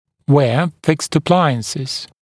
[weə fɪkst ə’plaɪənsɪz][уэа фикст э’плайэнсиз]носить несъемные аппараты